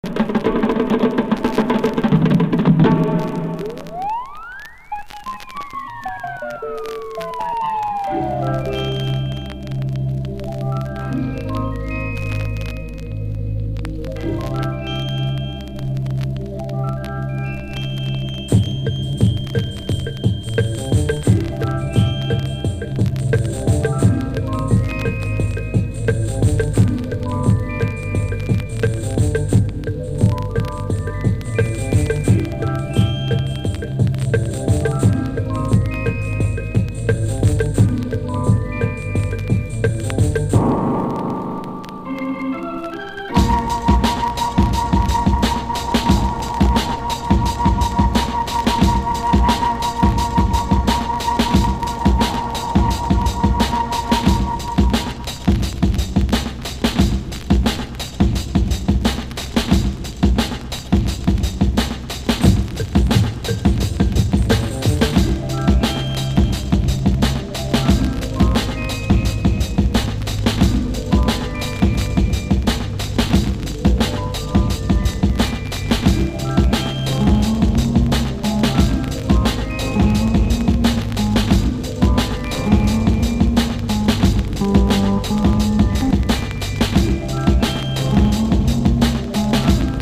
CLUB
最高のファンキー・オルガン・グルーヴ！